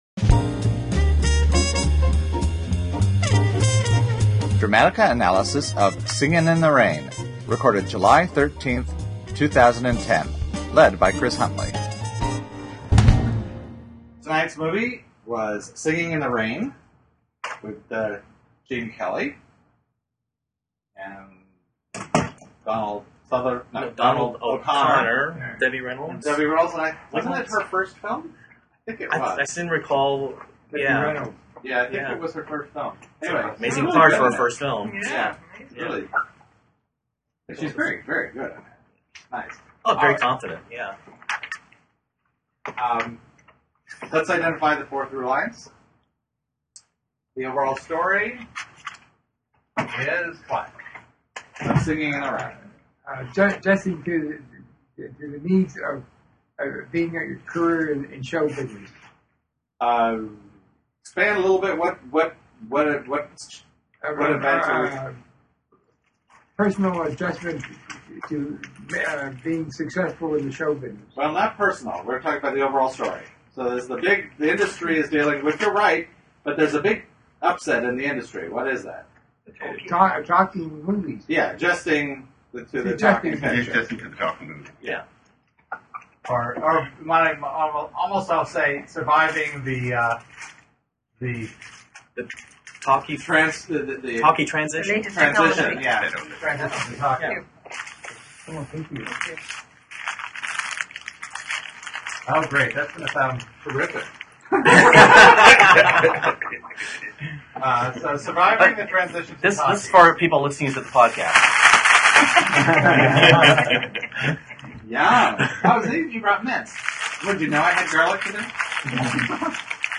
Delightful recordings of Dramatica Users attempting to better understand what makes great stories so great. Each podcast focuses on a popular or critically-acclaimed film. By breaking down story into fine detail, the group gains a better appreciation of the theory and how better to apply the concepts into their own work.